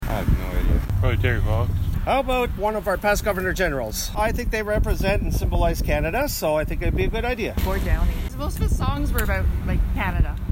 Our news team hit the streets to see who people think should be on the new bill.